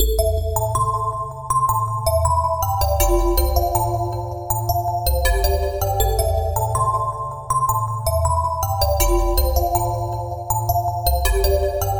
合成的钟声与回声
描述：有点类似于钟声的合成器。这是我的另一个常用乐器。非常轻盈、振奋人心的乐器，在铃铛上有一点回声。使用Logic制作
标签： 80 bpm Pop Loops Percussion Loops 2.02 MB wav Key : Unknown